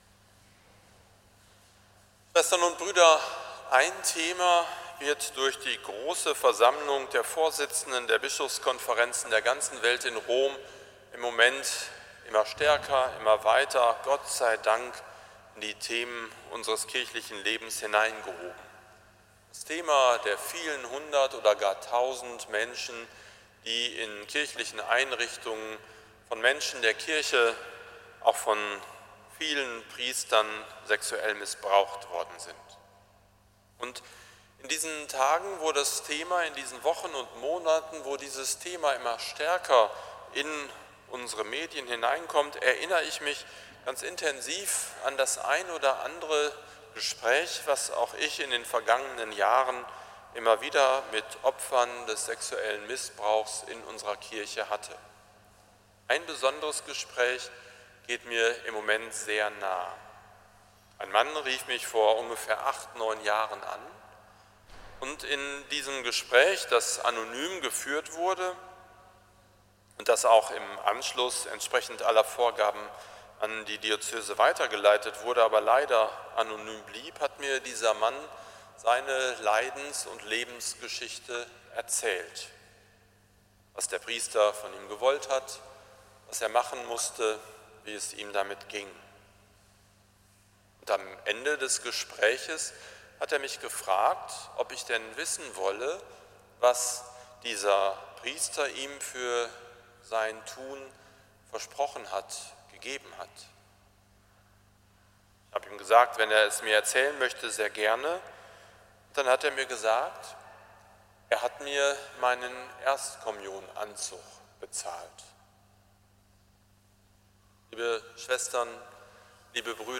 predigt-zum-7-sonntag-c-anti-missbrauchs-konferenz-der-bischoefe